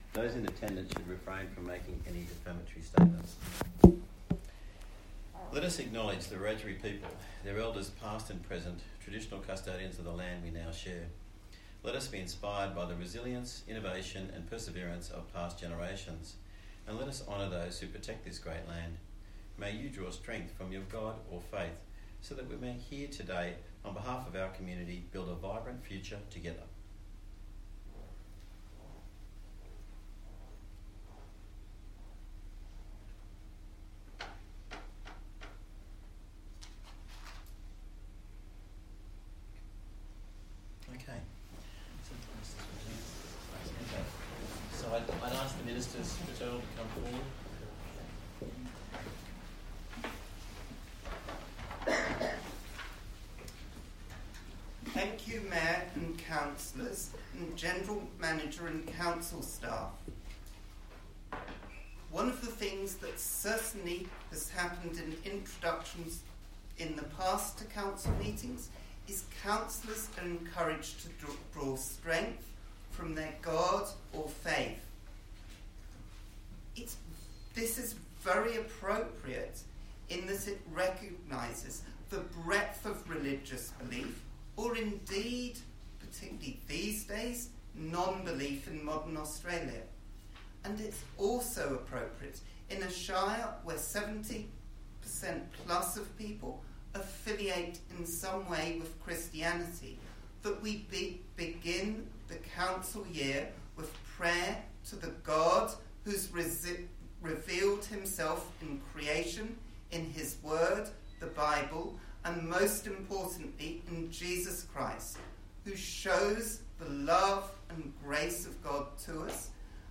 Bland Shire Council Chambers, 6 Shire Street, West Wyalong, 2671 View Map